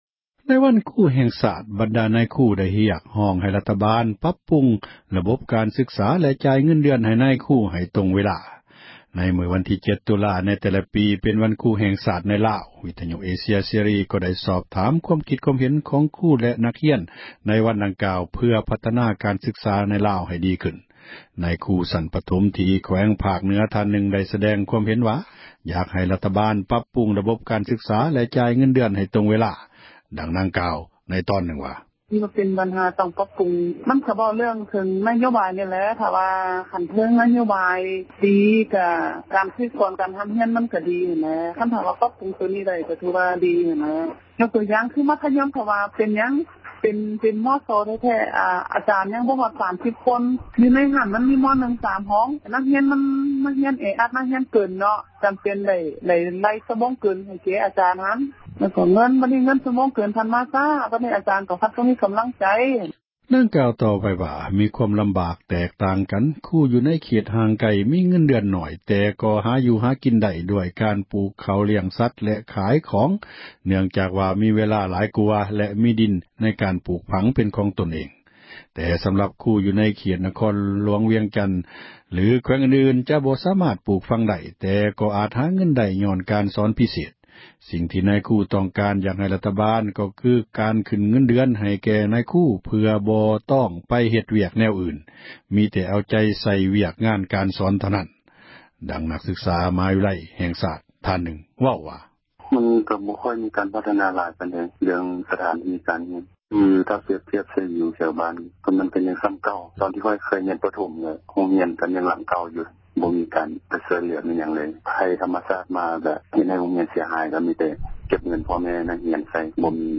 ວັນທີ 7 ຕຸລາ ເປັນວັນຄຣູແຫ່ງຊາດໃນລາວ ວິທຍຸເອເຊັຽເສຣີ ໄດ້ສອບຖາມຄວາມຄິດຄວາມເຫັນ ຂອງຄຣູແລະນັກຮຽນໃນວັນດັ່ງກ່າວວ່າ. ເພື່ອພັທນາການສຶກສາໃນລາວໃຫ້ດີຂື້ນ ນາຍຄຣູຊັ້ນປະຖົມທີ່ແຂວງພາກເຫນືອທ່ານນື່ງໄດ້ສະແດງ ຄວາມເຫັນວ່າ ຢາກໃຫ້ຣັຖບານປັບປຸງ ຣະບົບການສຶກສາແລະຈ່າຍເງິນເດືອນໃຫ້ຕຣົງເວລາ. ນາງເວົ້າວ່າ: